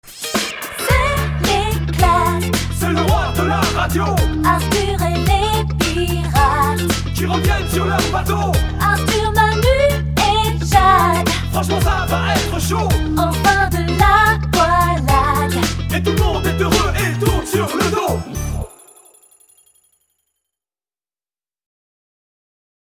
Chanteuse
Jingle chanté